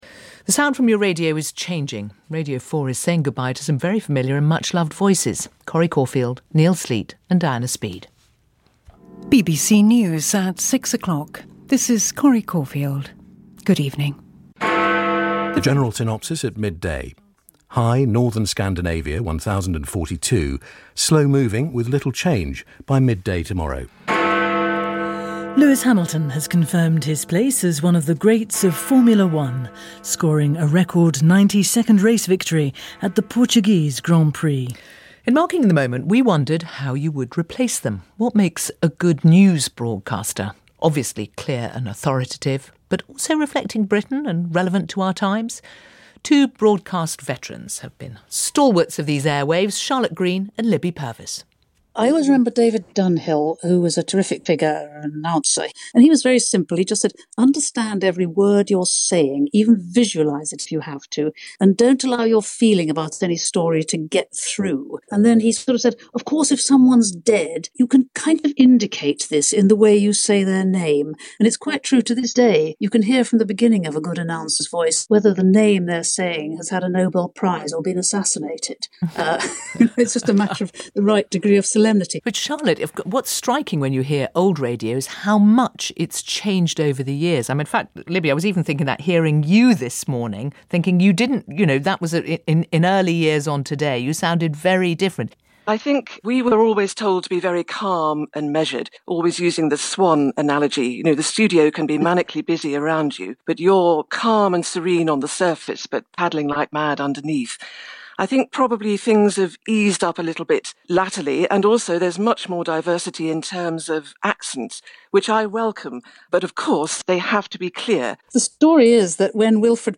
Radio 4 news - change and style as heard on The World at One - 2020